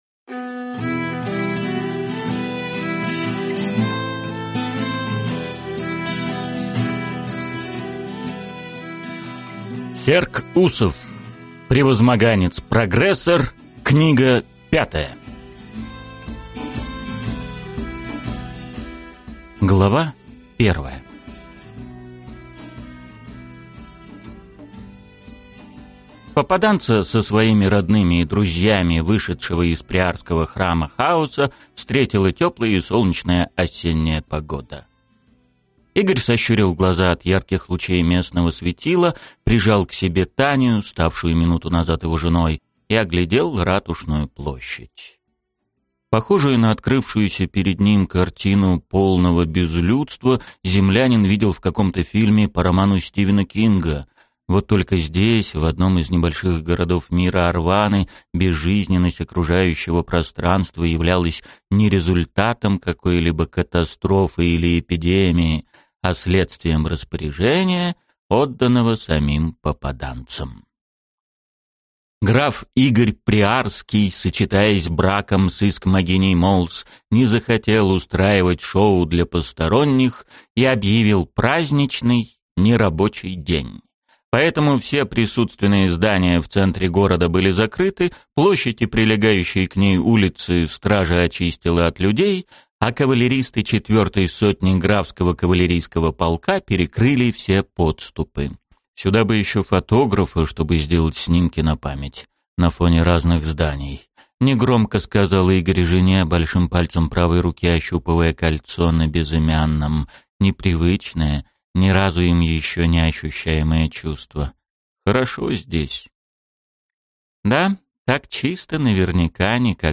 Aудиокнига Превозмоганец-прогрессор.